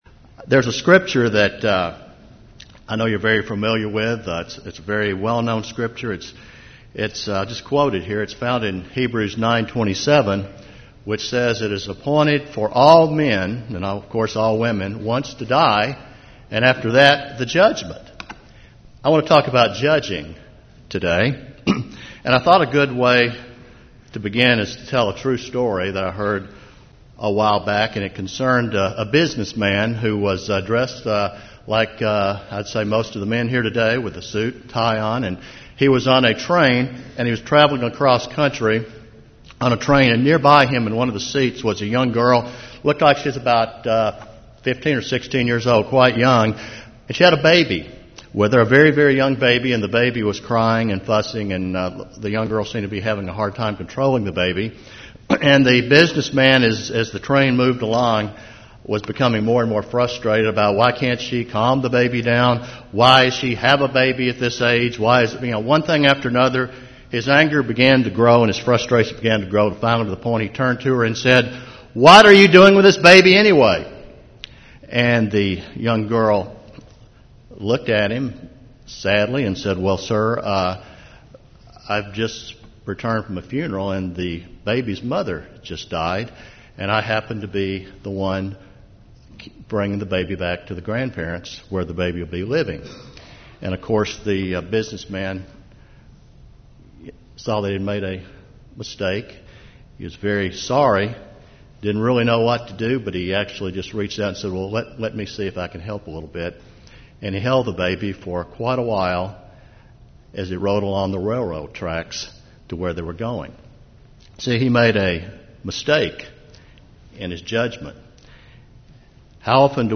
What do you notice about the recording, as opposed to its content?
This sermon was given at the New Braunfels, Texas 2012 Feast site.